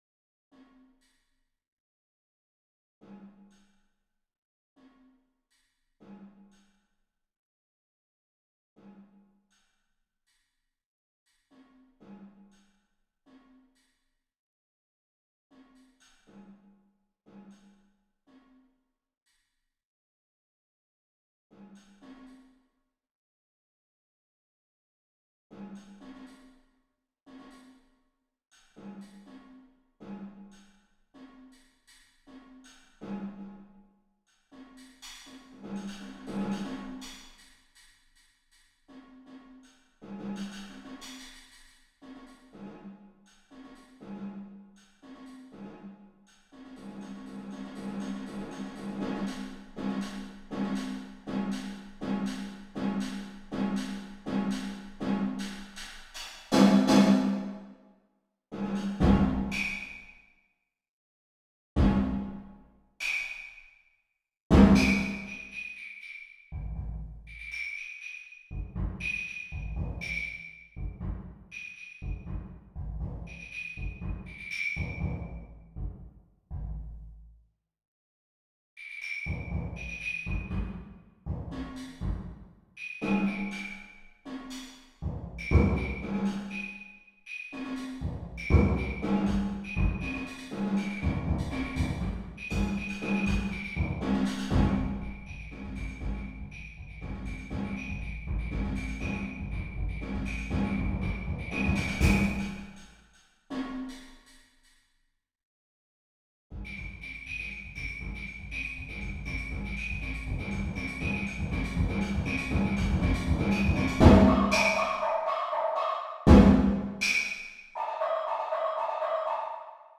Genre: Percussion Ensemble
# of Players: 8
Player 1 (high snare drum)
Player 2 (low snare drum)
Player 3 (high kick drum, high claves)
Player 4 (low kick drum, low claves)
Player 5 (high temple block, high woodblock)
Player 6 (low temple block, low woodblock)
Player 7 (high concert bass drum)
Player 8 (low concert bass drum)